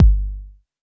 ●Bass Drum「909_K」
ドラムパーカション単音サウンドをDVD&CD-ROM3枚組に収録